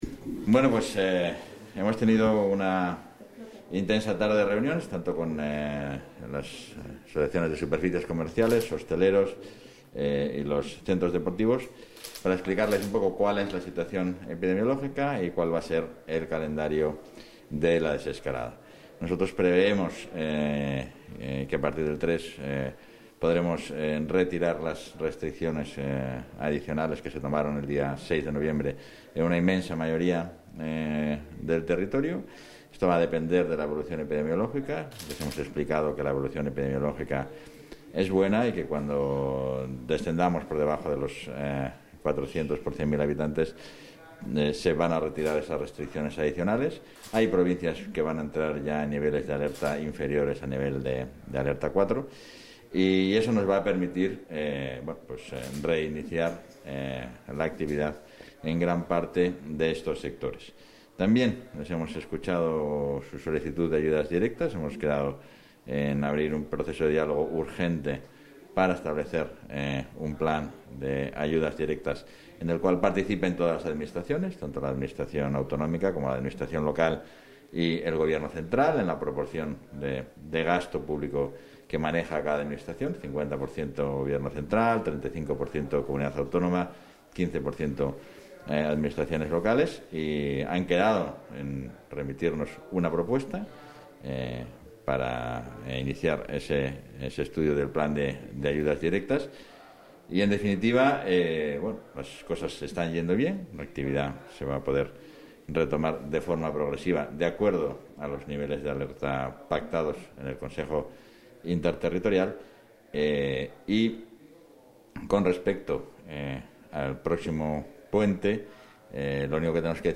Valoración del vicepresidente y portavoz.